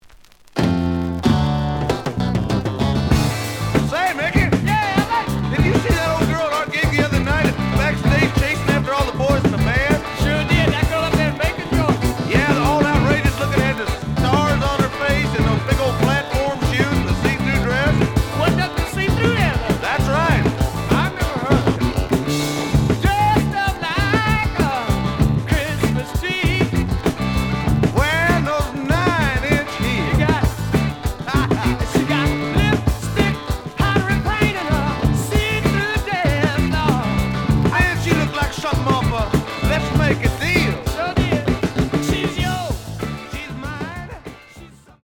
The audio sample is recorded from the actual item.
●Genre: Rock / Pop
Looks good, but slight noise on both sides.)